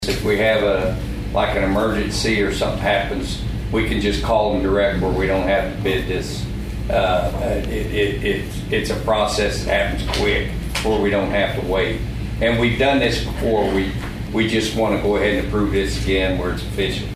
Associate Commissioner Kary Buckley says the benefit of the list is that the county can act quickly in an emergency.